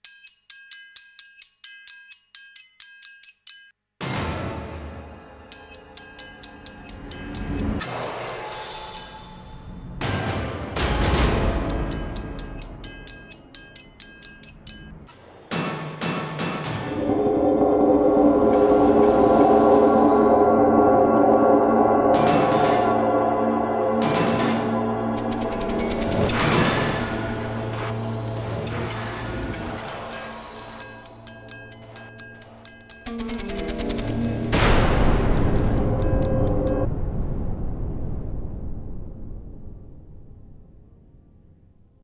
melodía
sintonía
Sonidos: Música